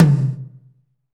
Index of /90_sSampleCDs/Northstar - Drumscapes Roland/DRM_Techno Rock/TOM_F_T Toms x
TOM F T H0NR.wav